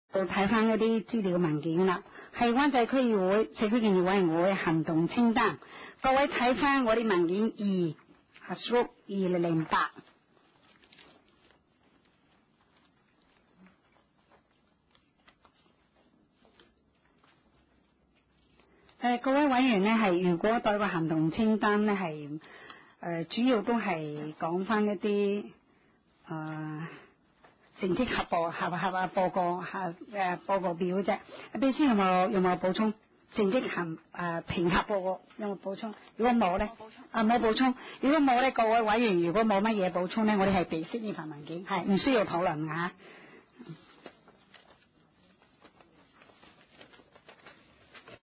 社區建設委員會第三次會議
灣仔民政事務處區議會會議室